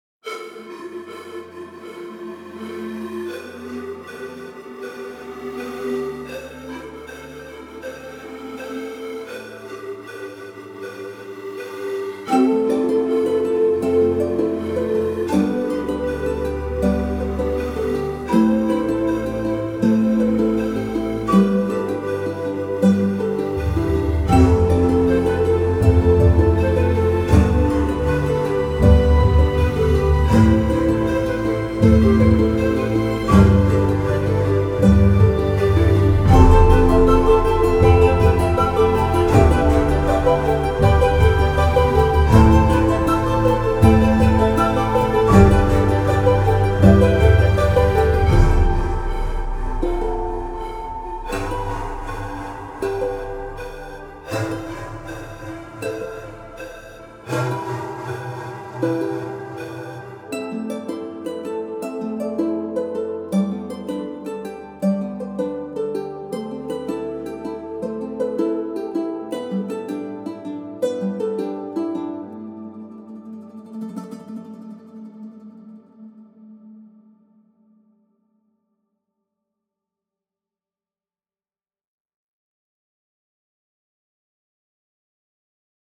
Authentic South American sounds